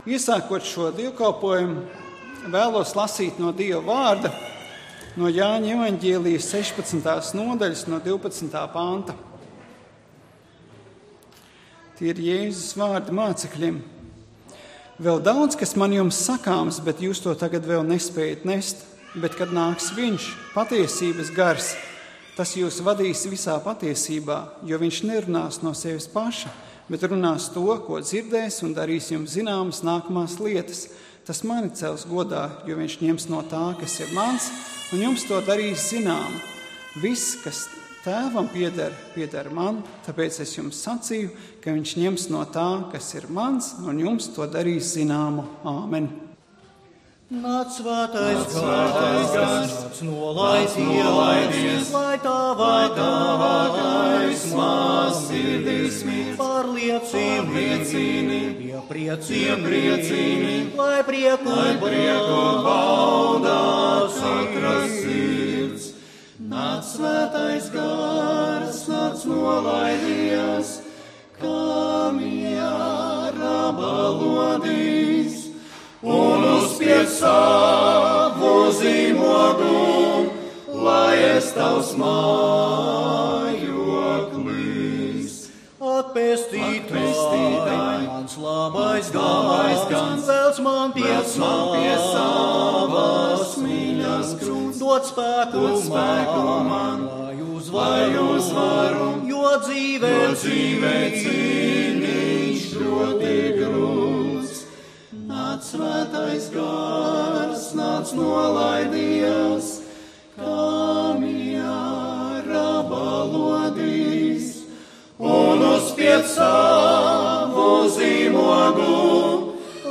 Svētrunas